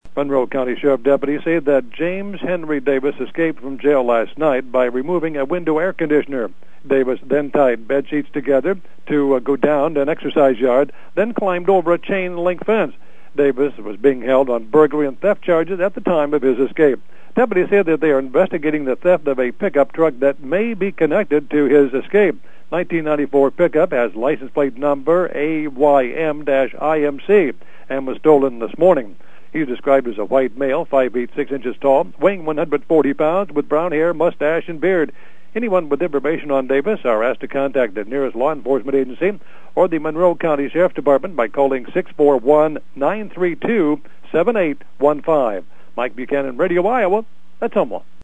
report.